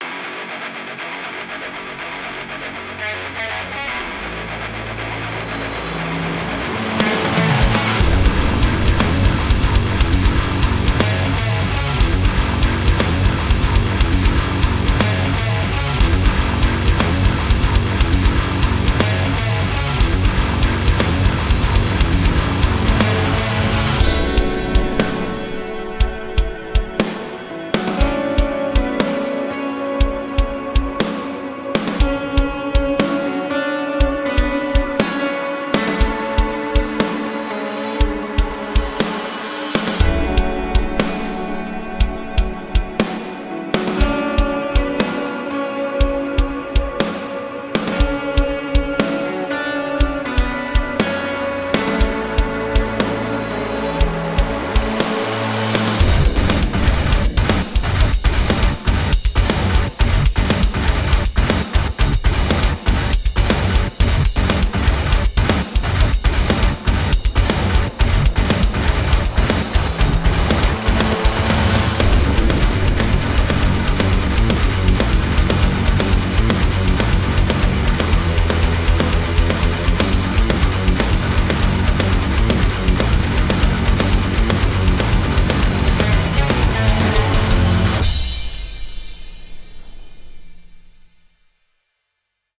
Get ready to melt your speakers with the Metalcore inferno